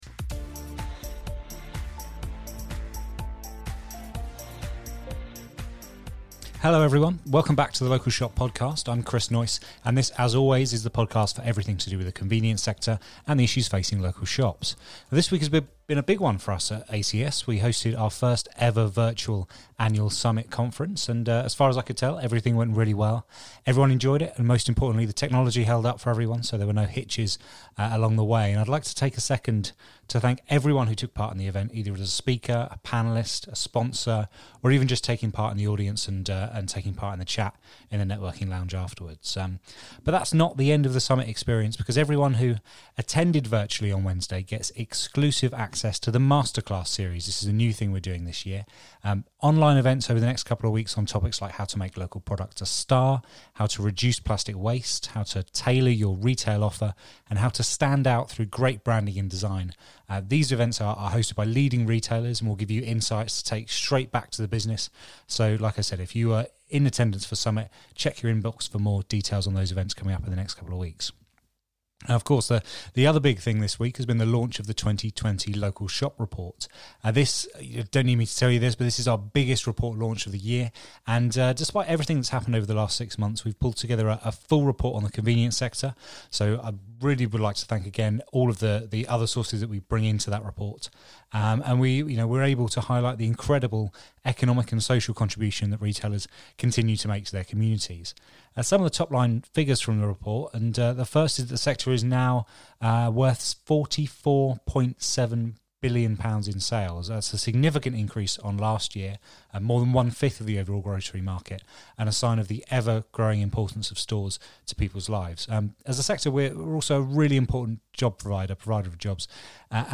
featuring a discussion
an expert panel